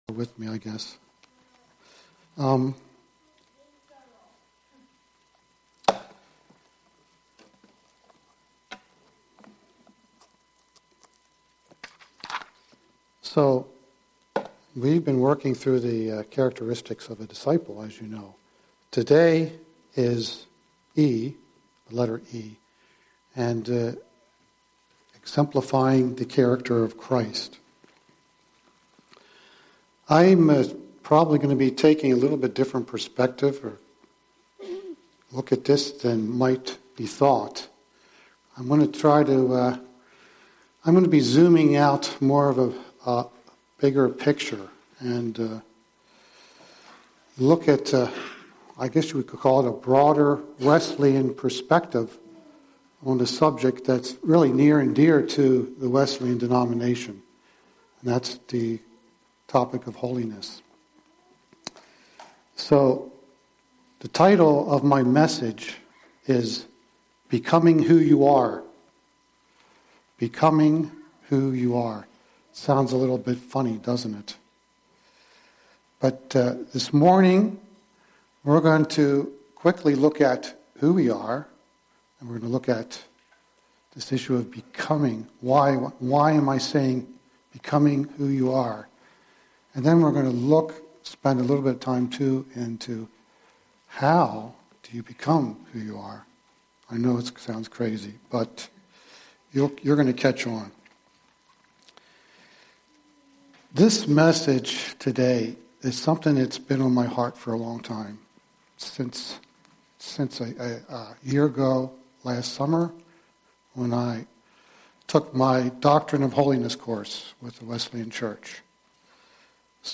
Sunday Messages | Transformation Church